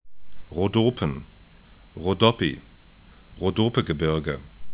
ro'do:pən
ro'do:pə-gə'bɪrgə